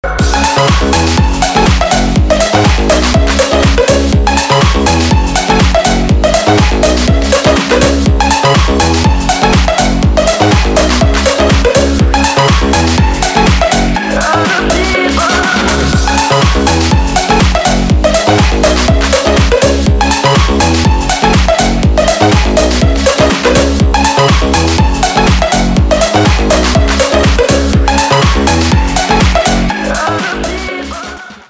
• Качество: 320, Stereo
dance
Electronic
Club House